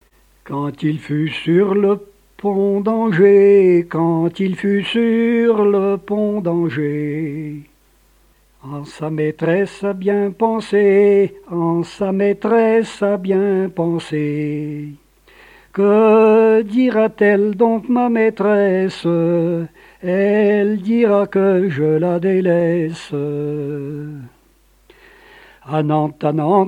Genre strophique
répertoire de chansons et airs à l'accordéon
Pièce musicale inédite